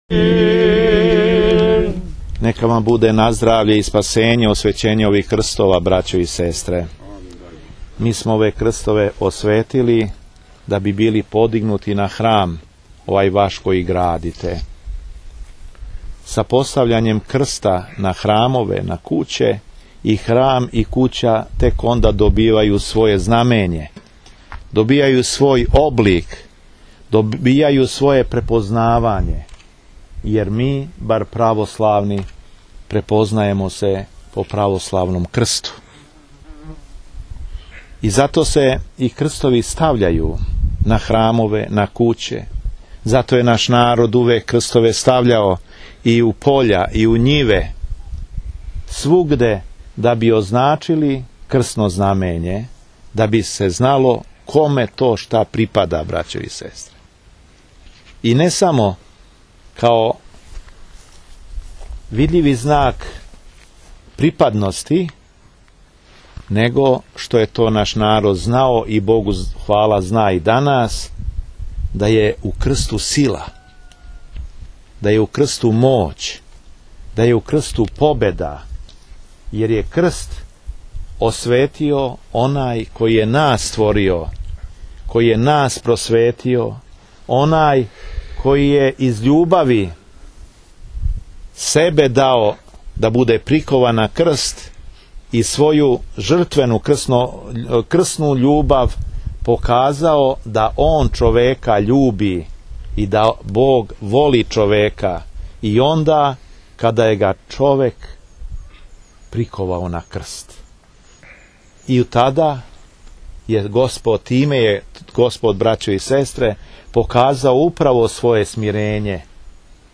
Беседа епископа шумадијског Г. Јована у Америћу